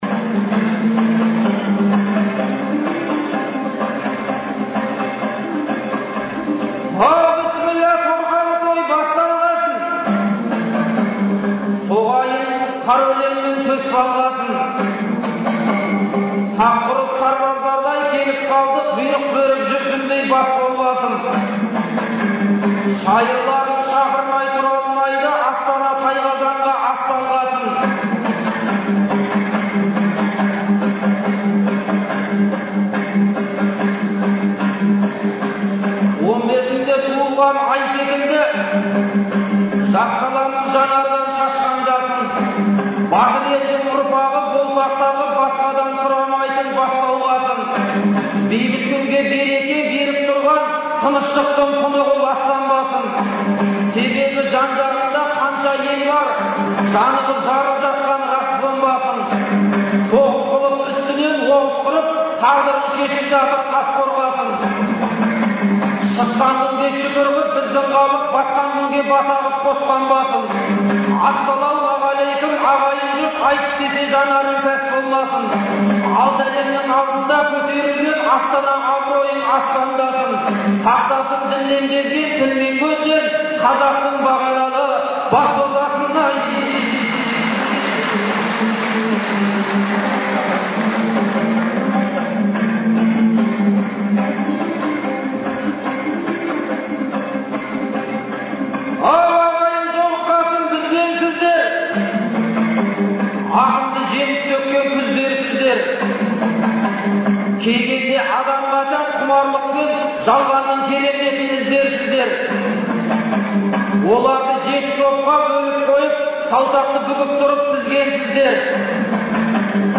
Шілденің 8-9-ы күндері Астанадағы «Қазақстан» орталық концерт залында «Ел, Елбасы, Астана» деген атпен Астана күніне орай ақындар айтысы өткен. Айтыстың алғашқы күні 20 ақын сөз сайыстырды.